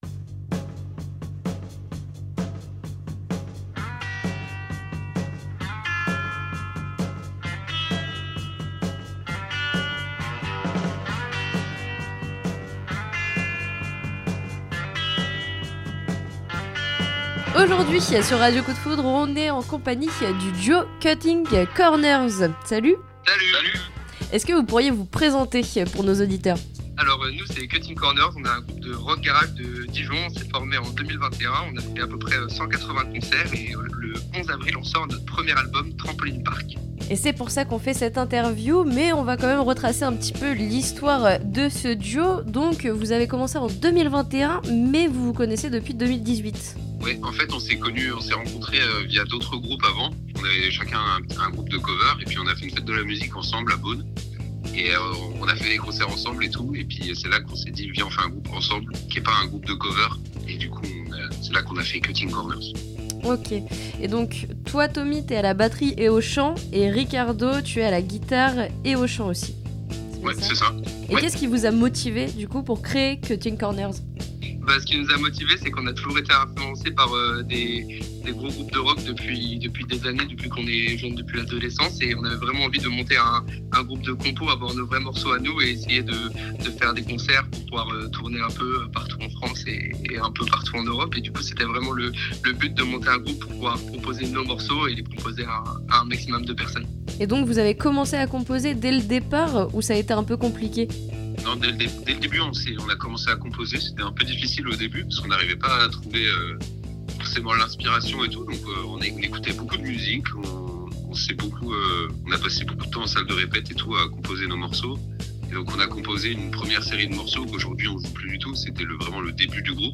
Interviews RCDF